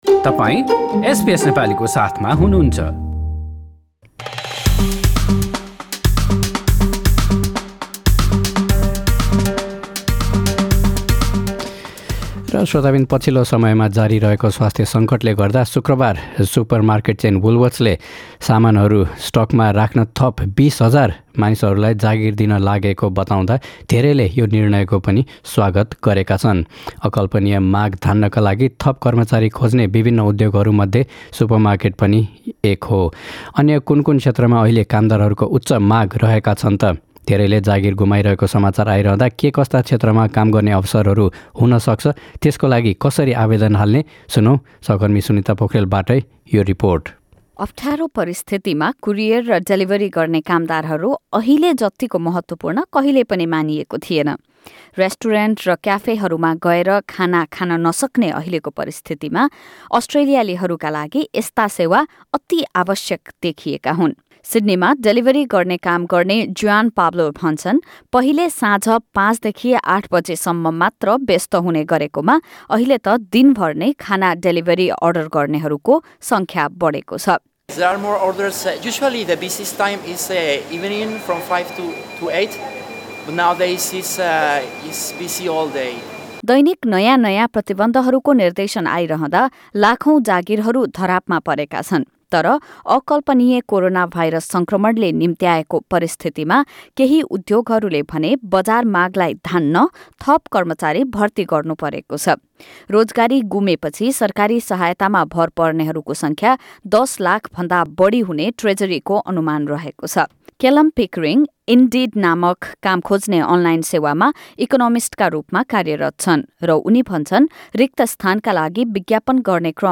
यसबारे एक रिपोर्ट यहाँ सुन्नुहोस्।